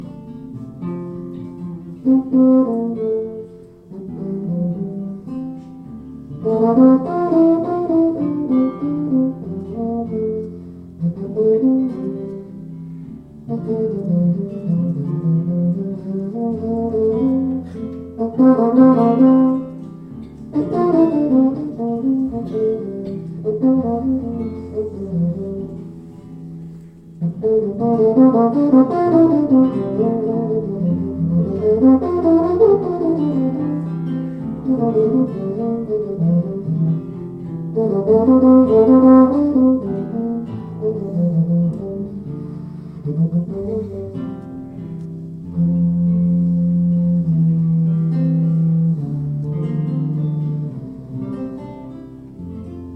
Michel Godard plays Monteverdi.